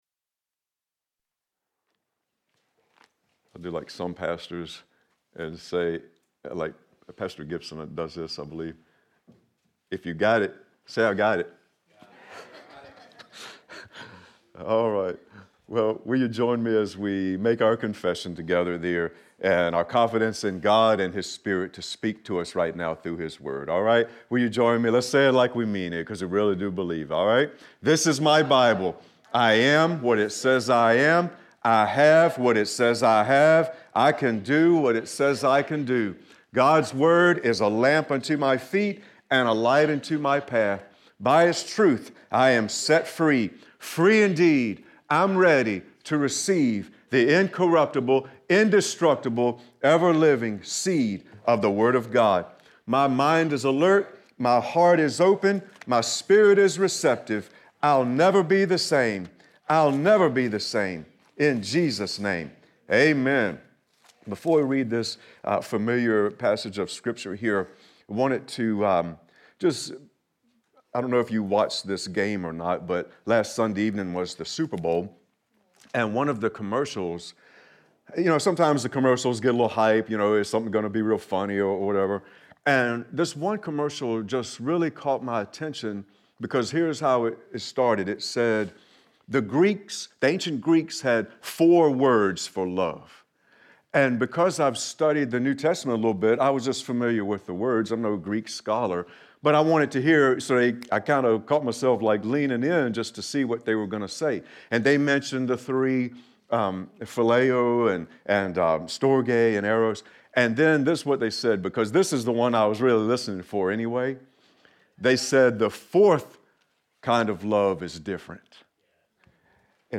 Sermon-Feb-9-2020-Love-Gives.mp3